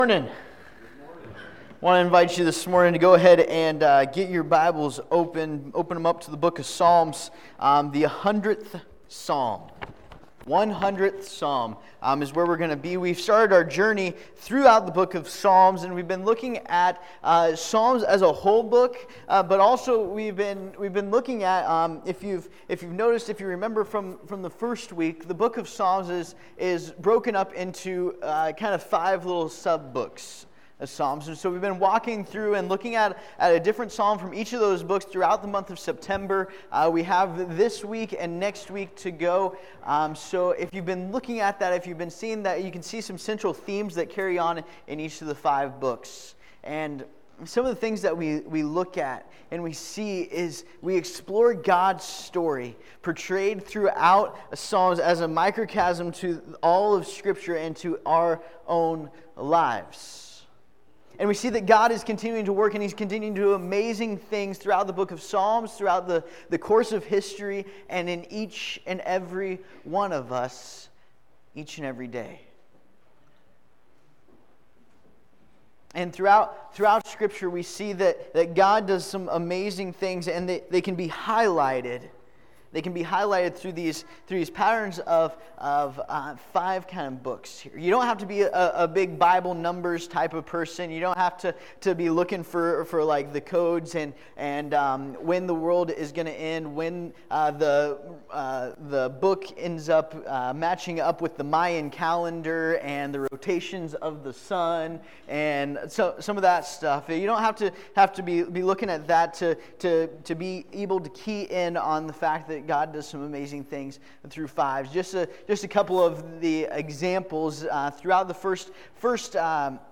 Psalm 100 Service Type: Sunday Morning Topics